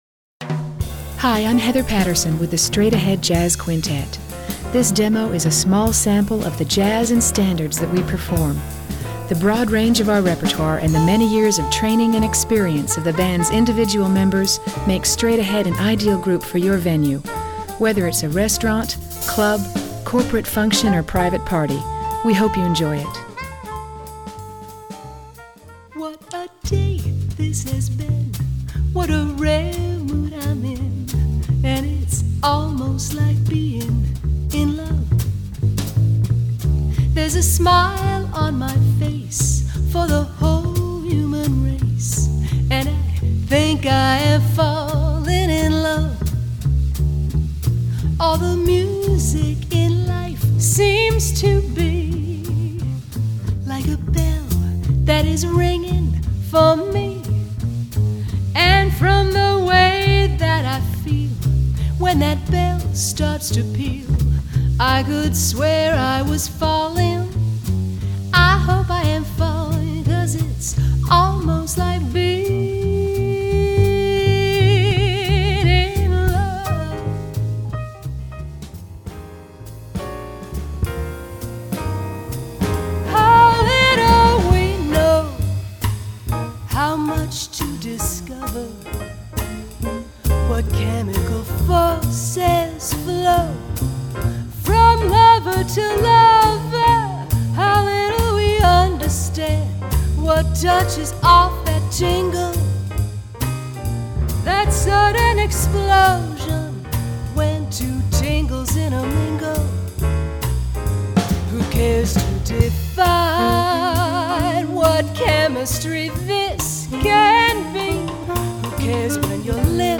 featuring the luxurious voice
saxophone